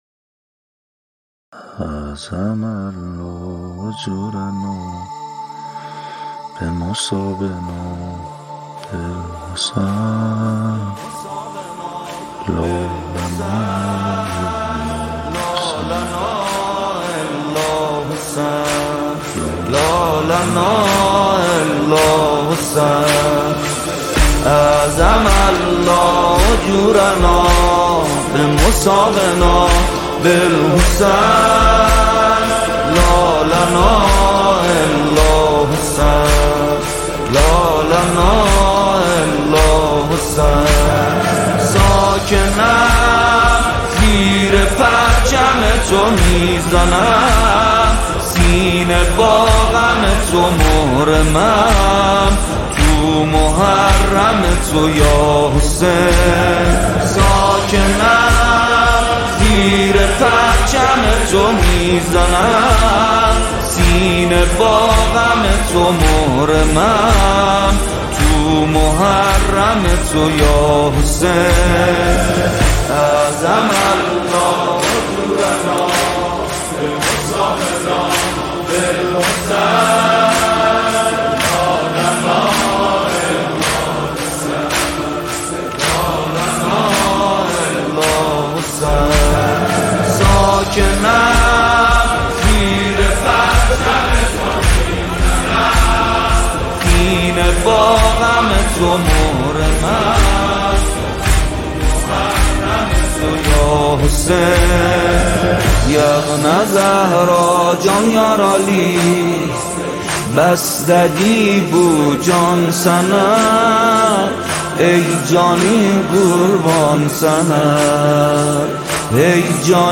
مداحی آذری
نوحه ترکی